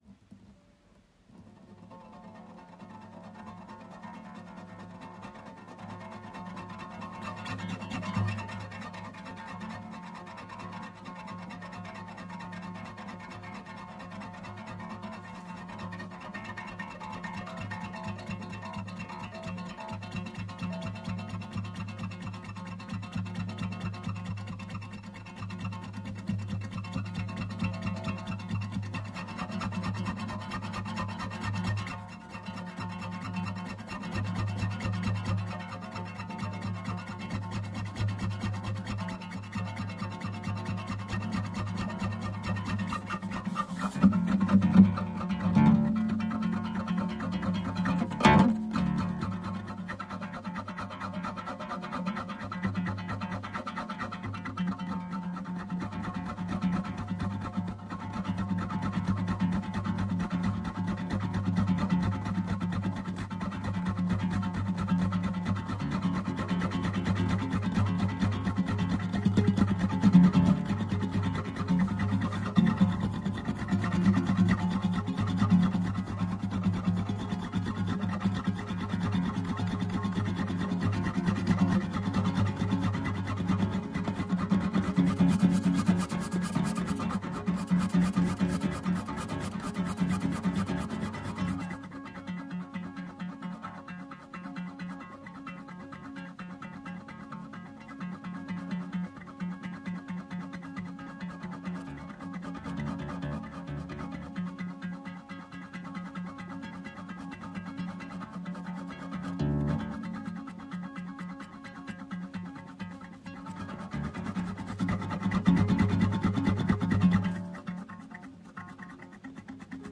#100, 10 minutes, minimalist improvisatory piece for guitar (SCORE AND AUDIO)